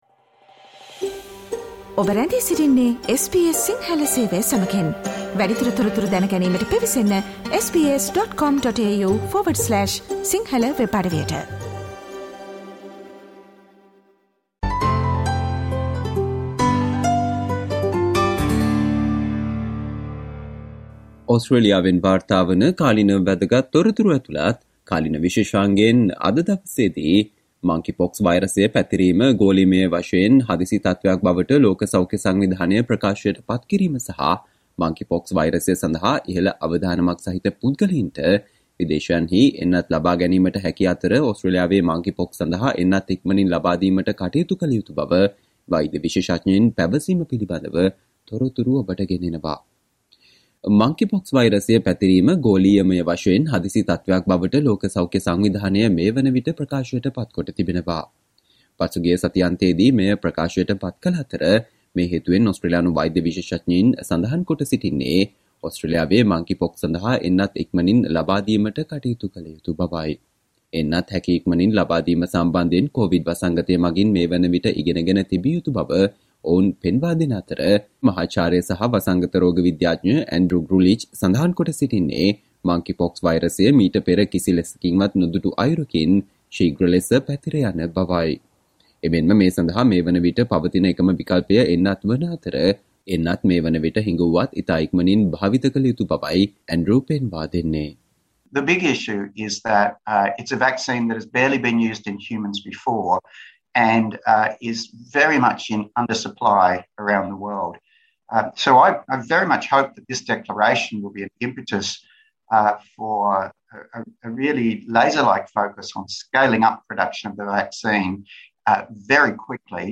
ජූලි 26 වන දා සඳුදා ප්‍රචාරය වූ SBS සිංහල සේවයේ කාලීන තොරතුරු විශේෂාංගයට සවන්දෙන්න.